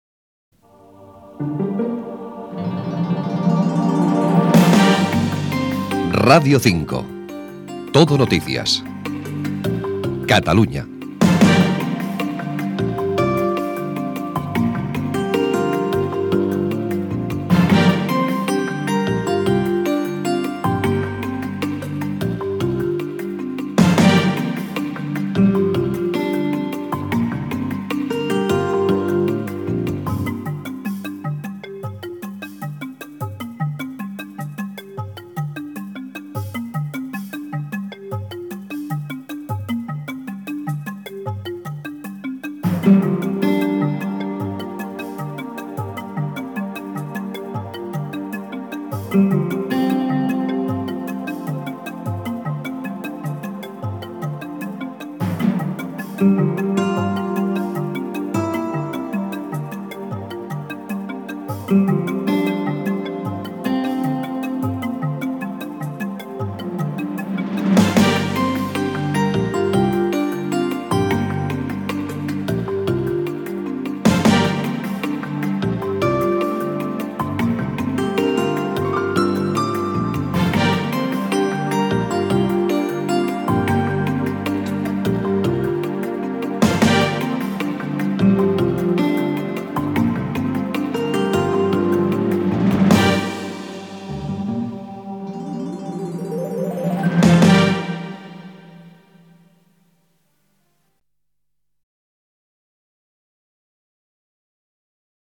Indicatiu de l'emissora i cua musical
FM